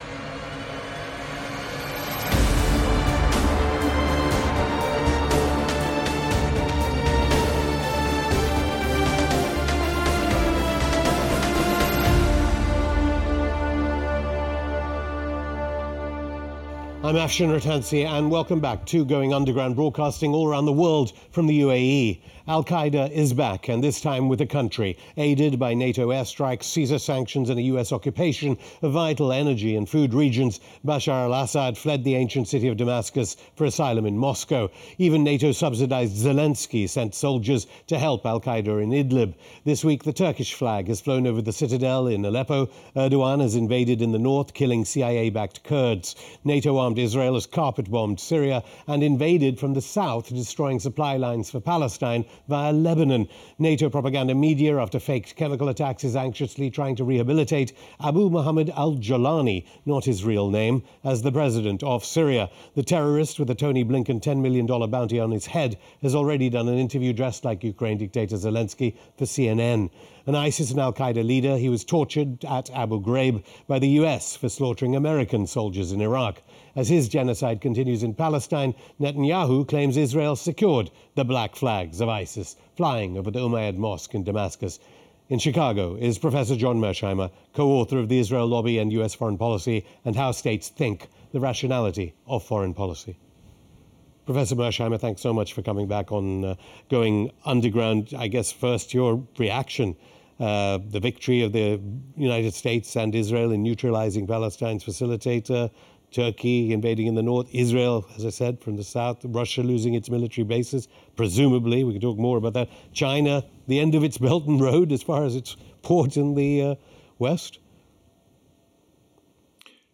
Prof. John Mearsheimer on the Fall of Assad: Syria Will Be in CHAOS For the Forseeable Future (Afshin Rattansi interviews John Mearsheimer; 14 Dec 2024) | Padverb